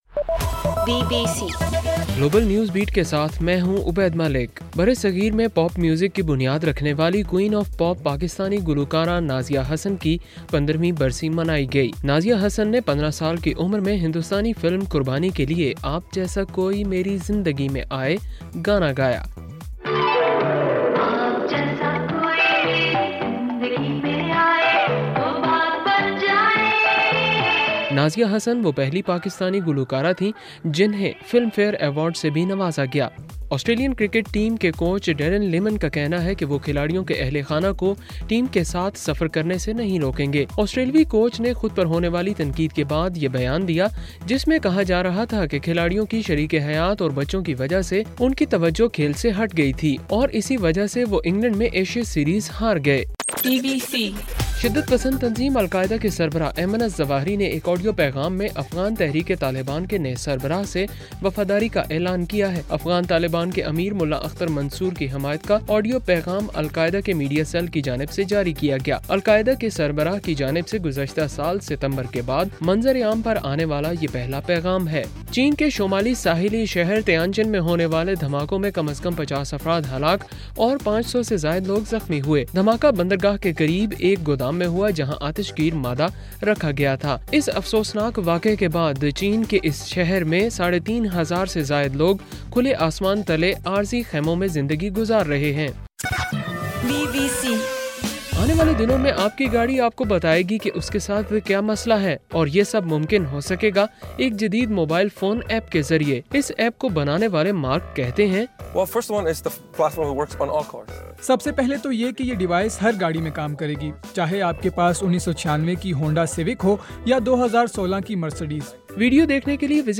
اگست 14: صبح 1 بجے کا گلوبل نیوز بیٹ بُلیٹن